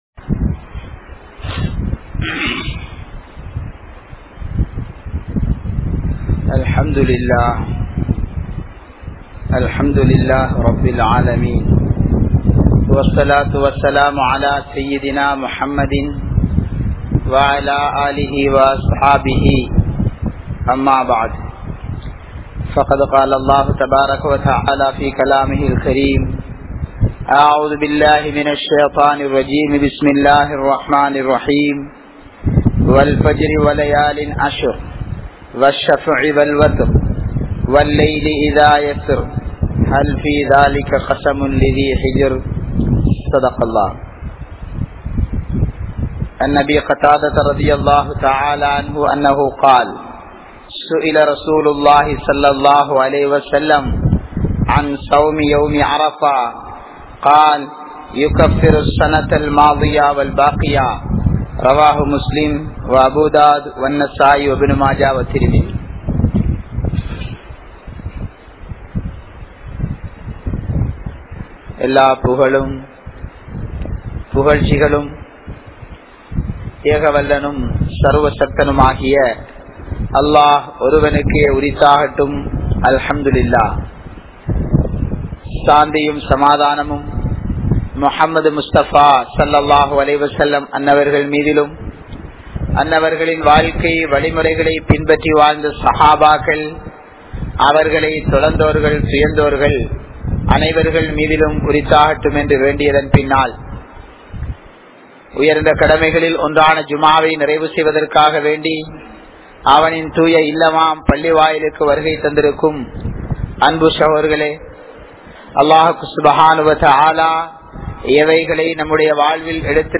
Muslimkalin Thoalvikaana Kaaranam (முஸ்லிம்களின் தோல்விக்கான காரணம்) | Audio Bayans | All Ceylon Muslim Youth Community | Addalaichenai